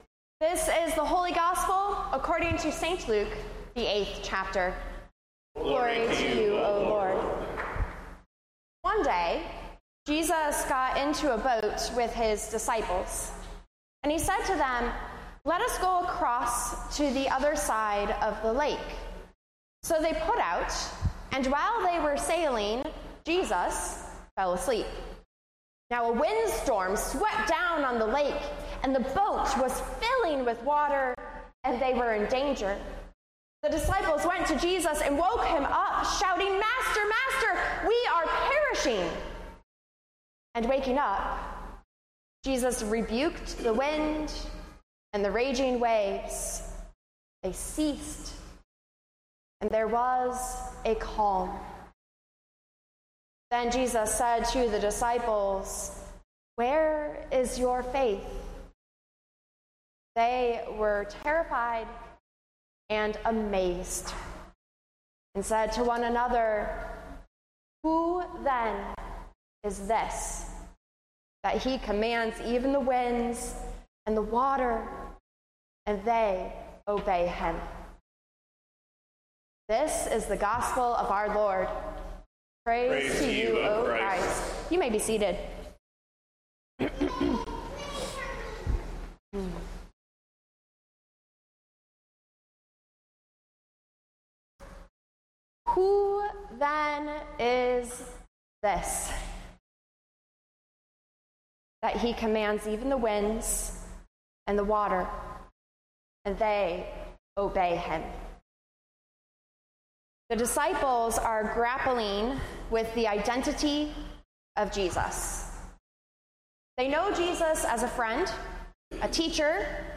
Sermons | Grace Evangelical Lutheran Church